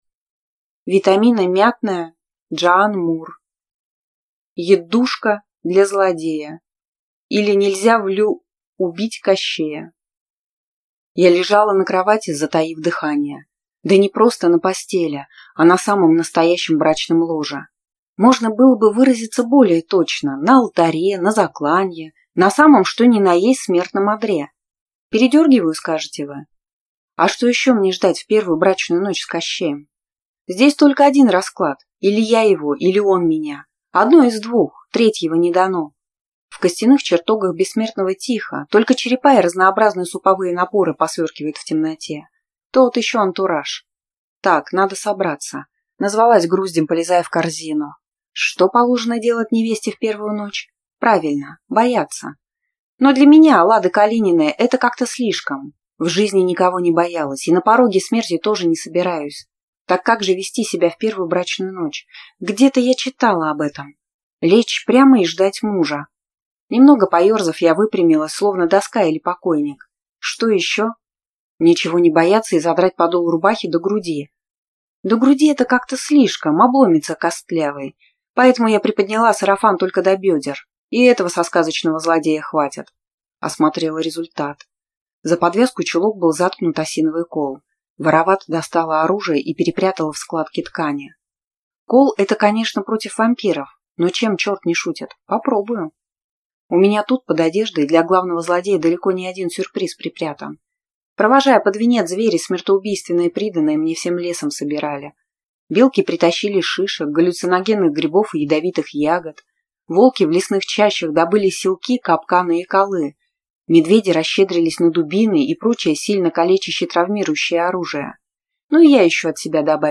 Аудиокнига Яддушка Для Злодея, Или Нельзя (Влю)Убить Кощея | Библиотека аудиокниг
Прослушать и бесплатно скачать фрагмент аудиокниги